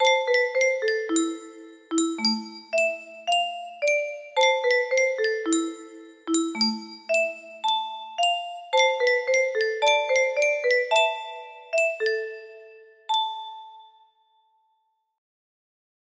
Mystery music box melody